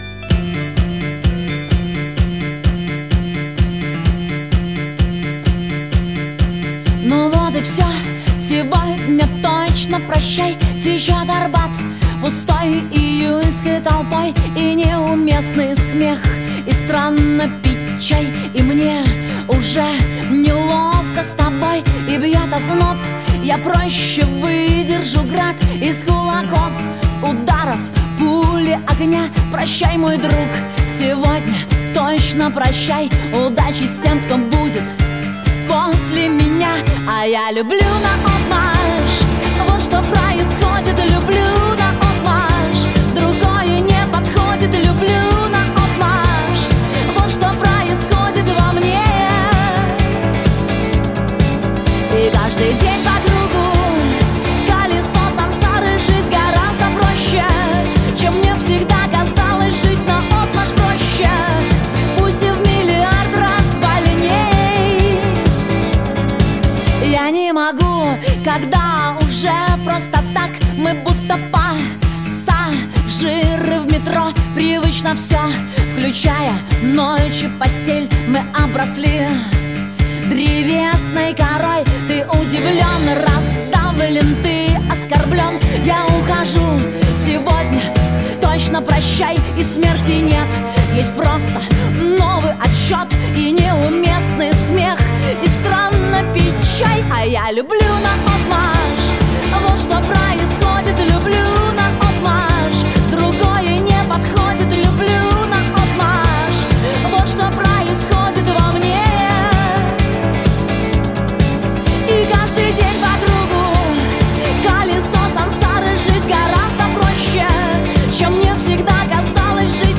За несколько часов до выхода на сцену в Светлогорске, Диана Арбенина побывала в студии местной радиостанции "Радио Ранак". Если пропустили прямой эфир - слушайте запись интервью!